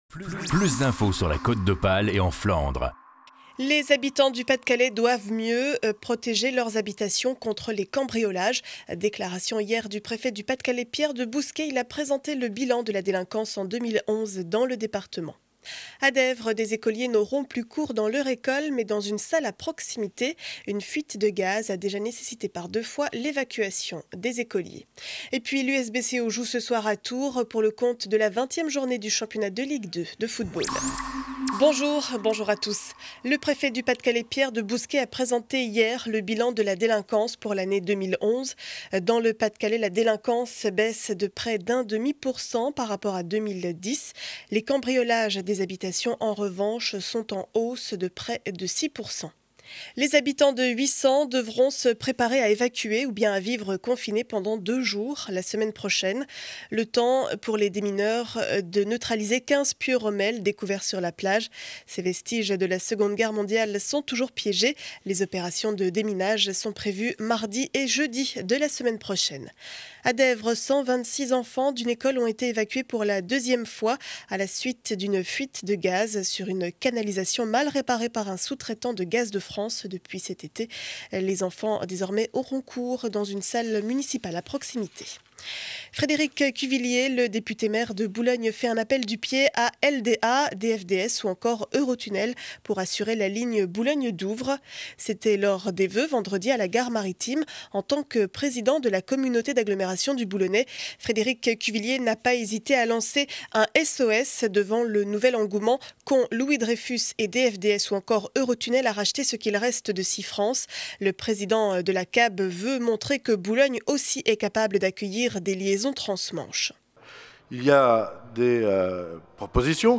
Journal du mercredi 18 janvier 7 heures 30 édition du Boulonnais.